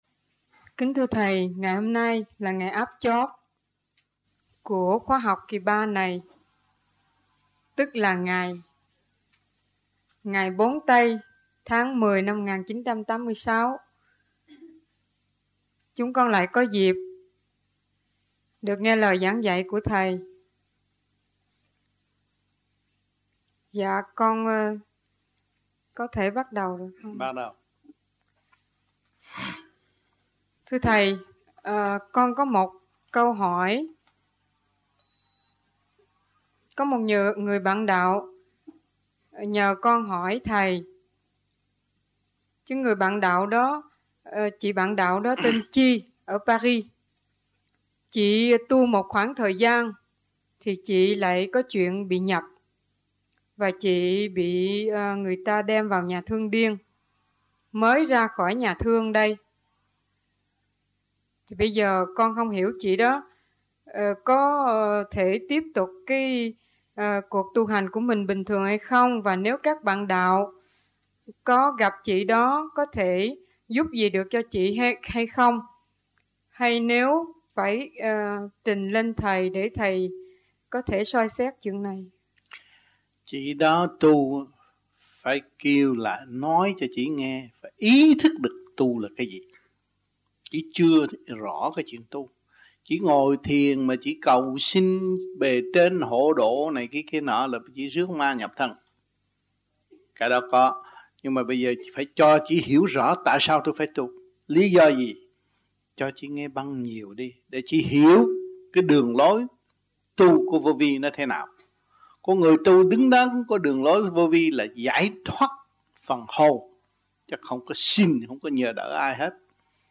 1986 Đàm Đạo
1986-10-04 - VẤN ĐẠO 05 - KHOÁ 3 - THIỀN VIỆN QUY THỨC